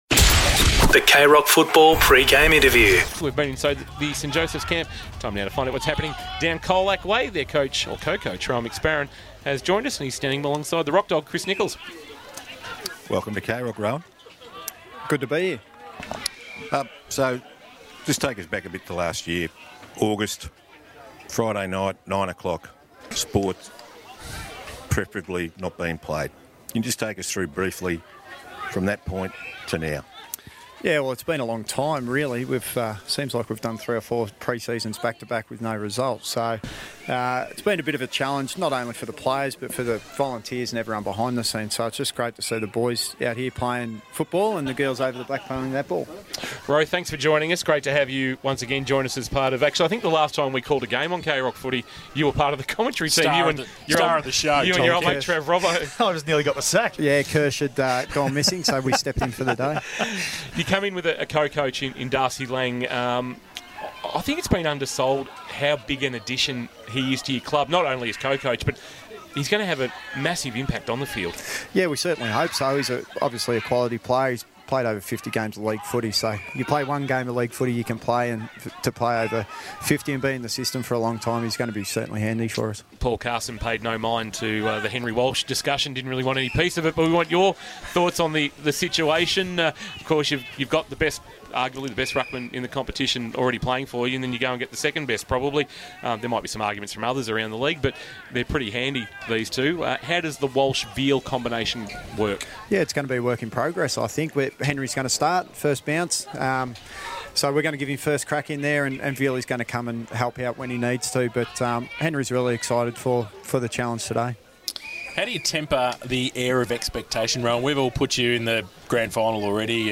2022 - GFL ROUND 1 - ST JOSEPH'S vs. COLAC: Pre-match Interview